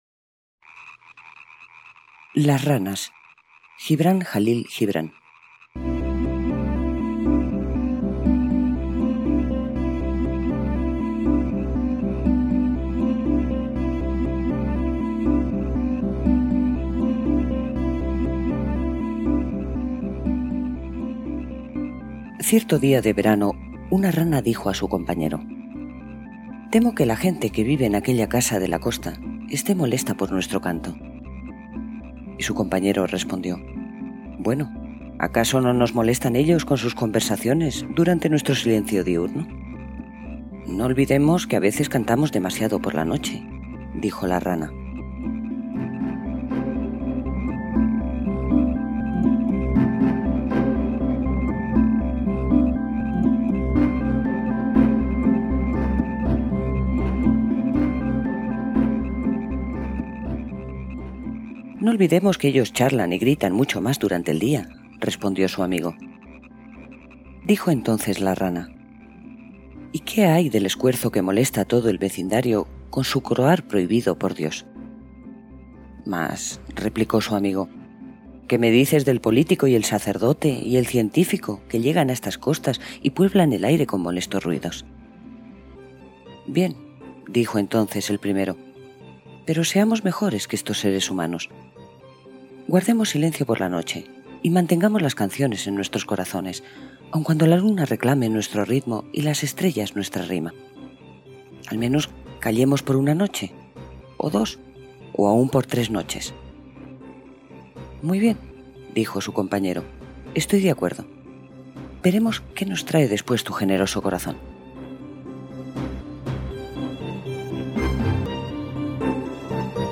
Las ranas, audiolibro
las-ranas.mp3